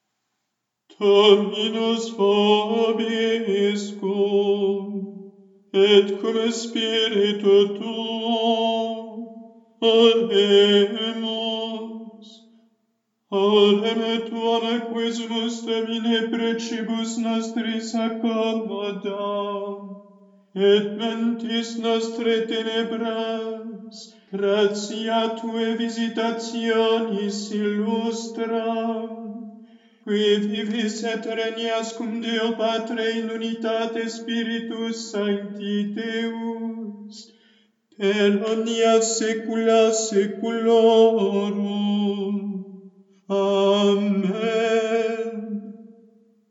Oratio